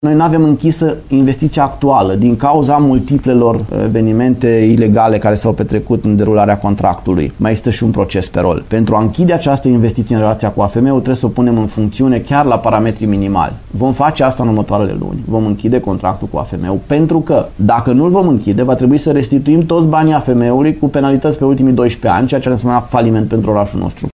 Proiectul a fost un eșec, iar stația nu funcționeză nici în prezent, astfel că Lugojul ar putea să fie obligat să înapoieze banii, la care se adaugă și o serie de penalități, spune primarul Lugojului, Claudiu Buciu.